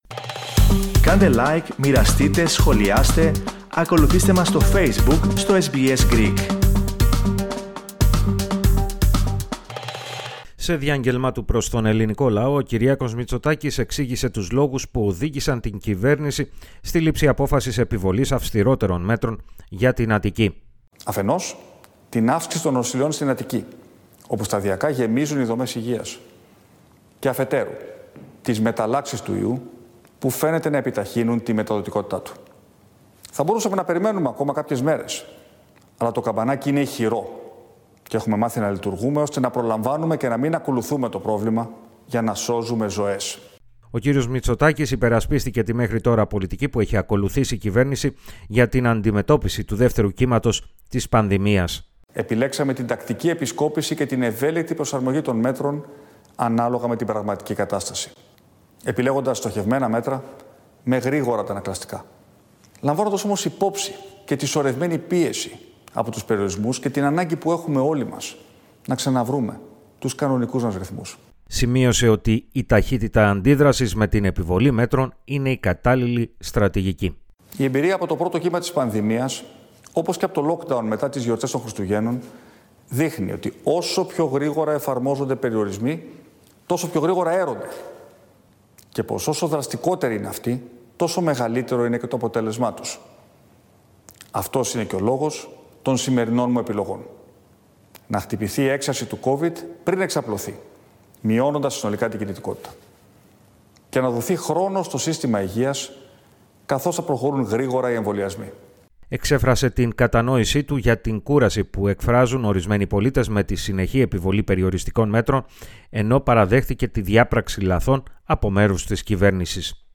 Την εφαρμογή νέων αυστηρότερων περιοριστικών μέτρων, από την Πέμπτη 11 Φεβρουαρίου, και μέχρι τις 28 του μηνός, προανήγγειλε ο πρωθυπουργός της Ελλάδας, Κυριάκος Μητσοτάκης. Περισσότερα ακούστε στην αναφορά